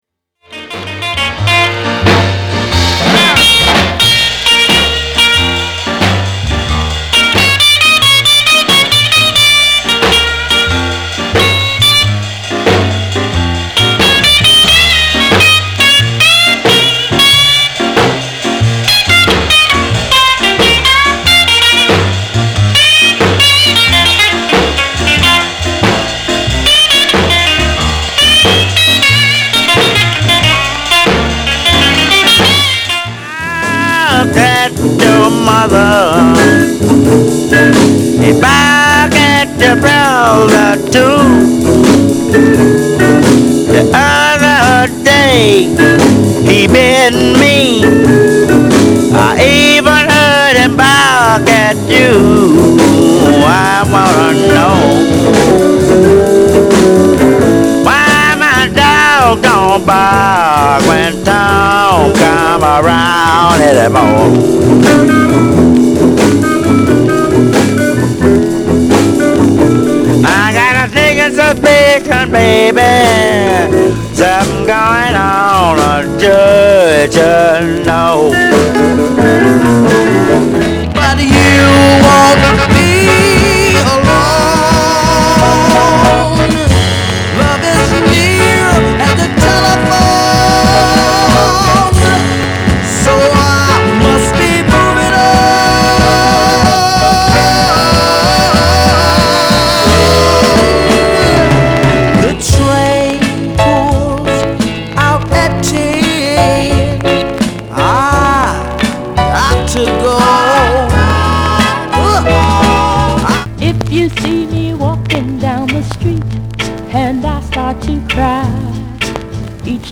類別 FUSION